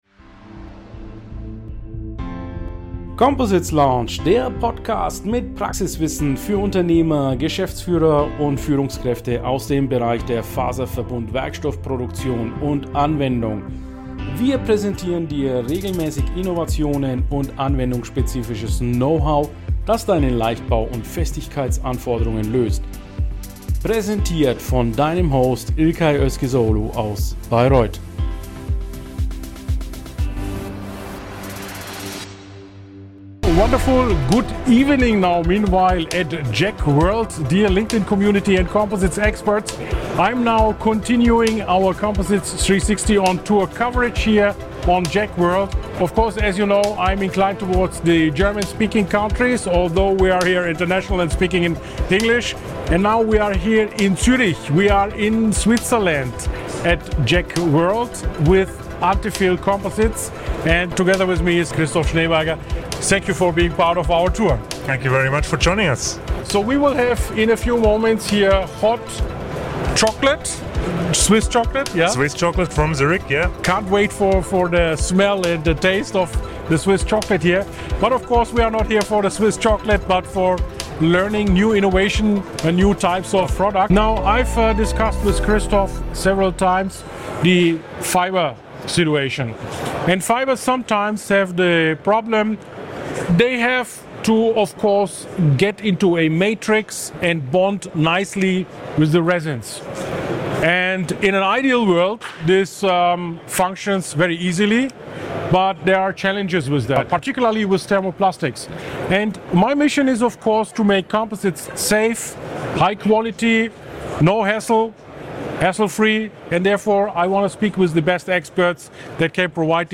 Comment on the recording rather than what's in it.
#123 Interview with Antefil Zurich during JEC World 2024 on Fibrecoating